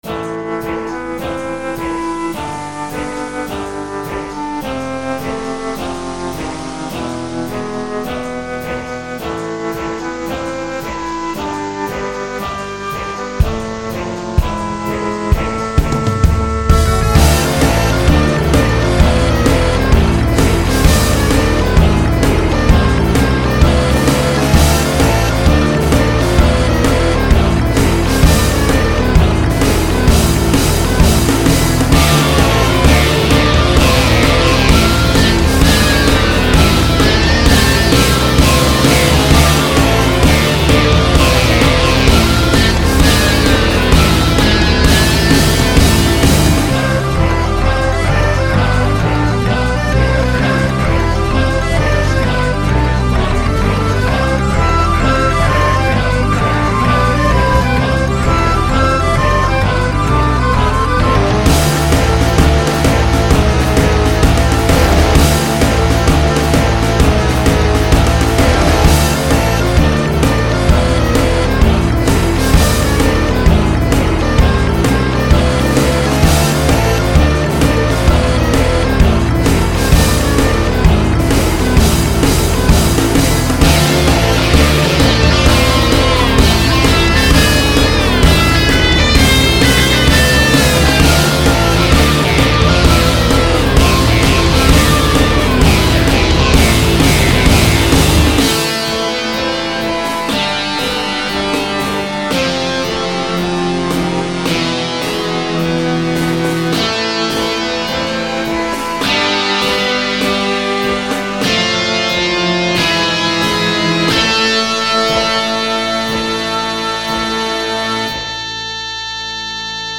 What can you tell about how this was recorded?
Not exactly the nicest-sounding cover I've ever made